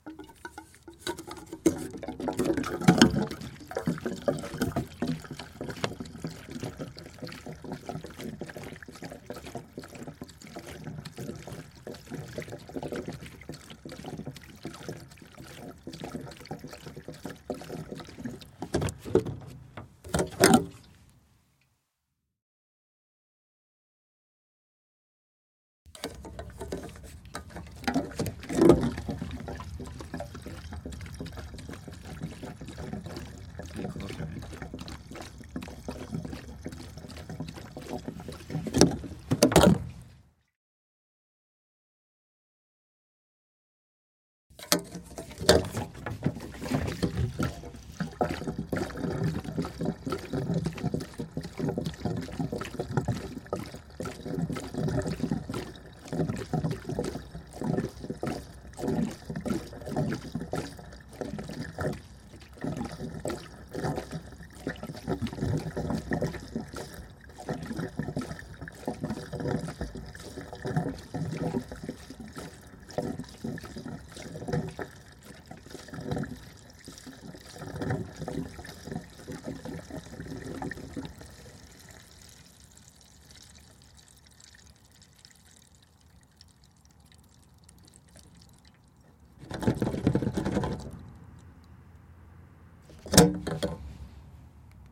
描述：塑料气体容器填充汽车罐汽车cu gurgle +汽车气帽remove.wav
Tag: 填充 容器 汽车 汩汩 塑料 删除 气体 汽车